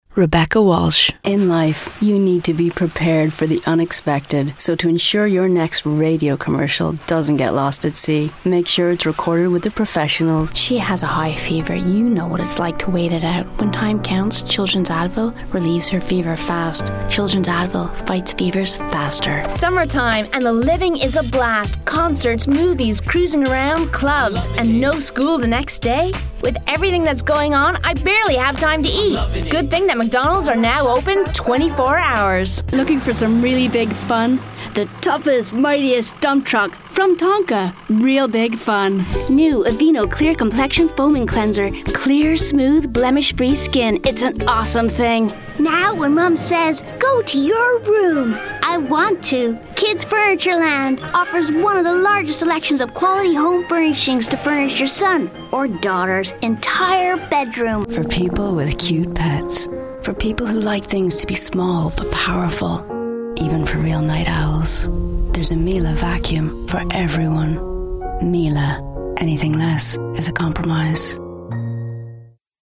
Showreel
Female / 20s, 30s / American, Canadian, Southern Irish / Transatlantic